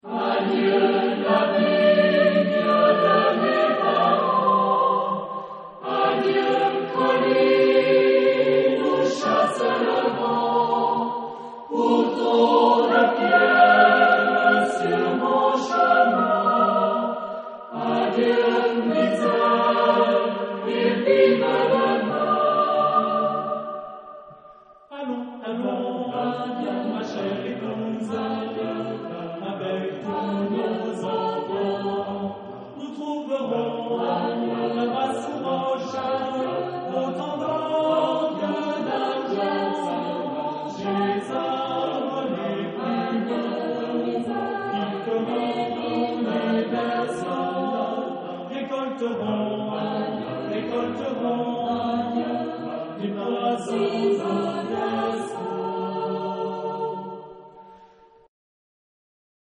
Epoque: 20th century
Genre-Style-Form: Secular ; Partsong
Mood of the piece: sorrowful ; catchy
Type of Choir: SATB  (4 mixed voices )
Tonality: G minor ; G major